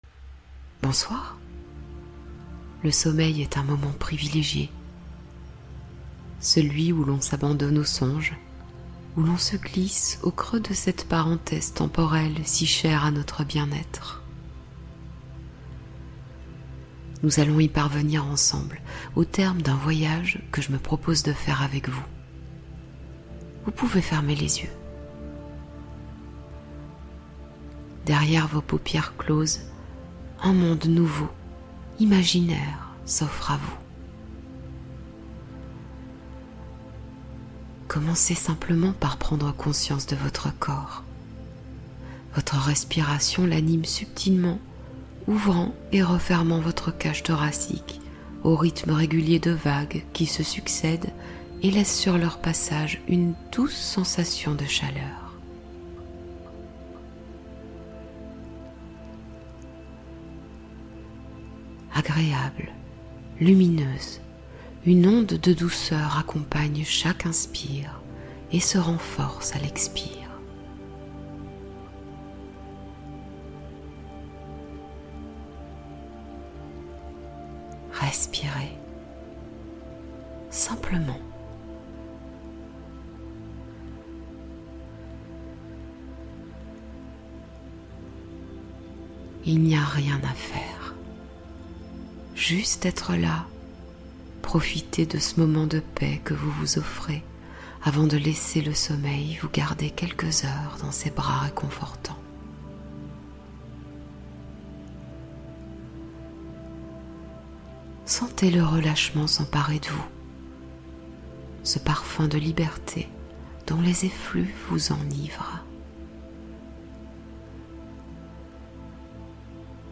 Soin Énergétique : Hypnose pour apaiser les ruminations et dormir enfin